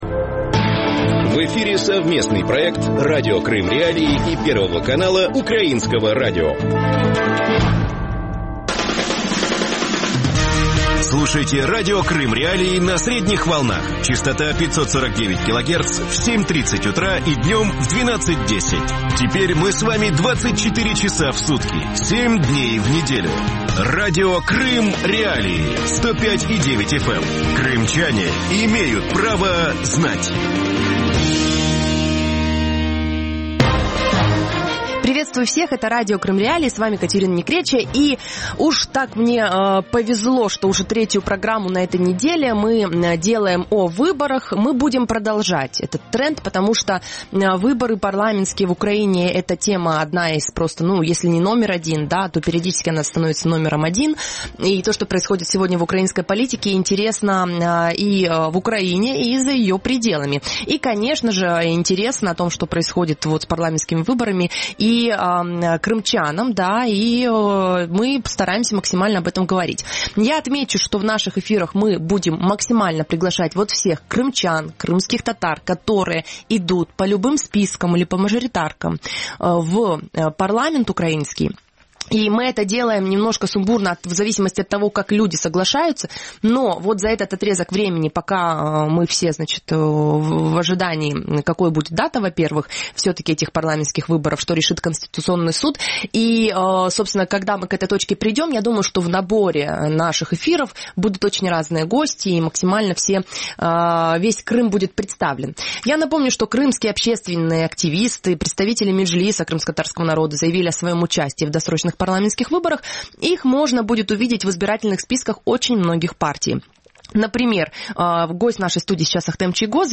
Почему Чийгоз выбрал партию «Европейская солидарность»? Гость эфира: Ахтем Чийгоз, заместитель главы Меджлиса крымскотатарского народа.